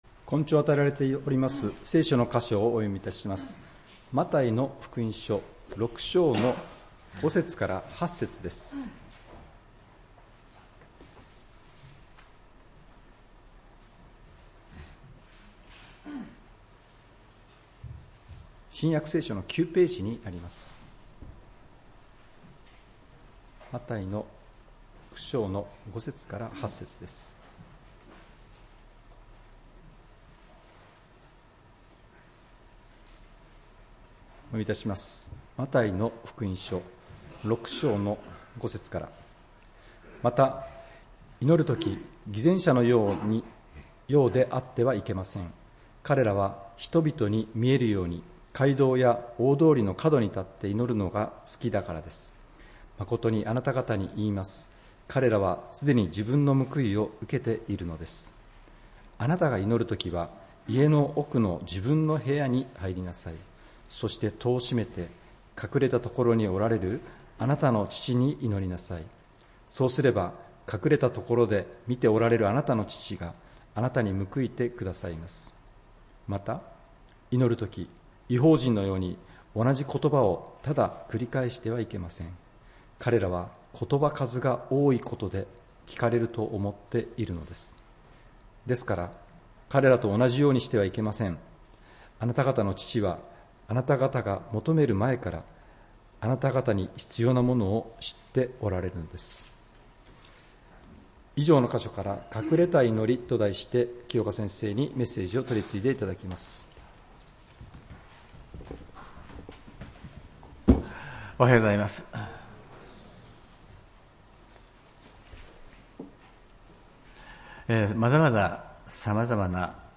礼拝メッセージ「隠れた祈り」(11月24日）